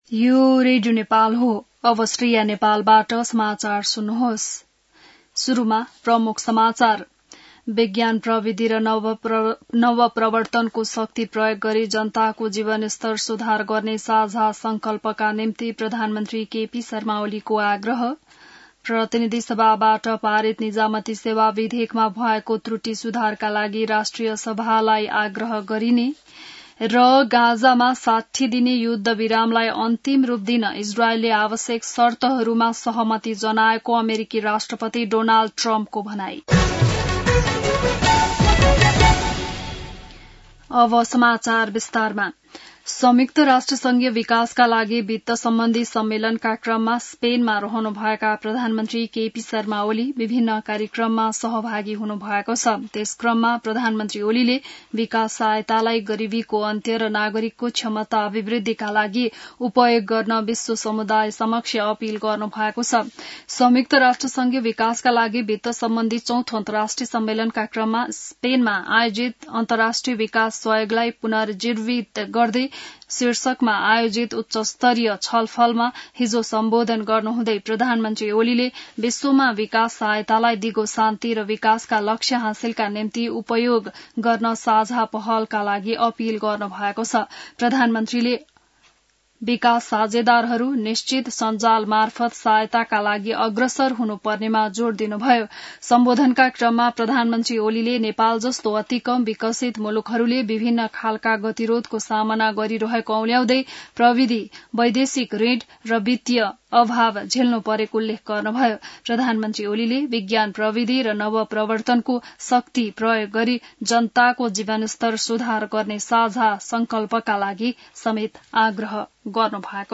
An online outlet of Nepal's national radio broadcaster
बिहान ९ बजेको नेपाली समाचार : १८ असार , २०८२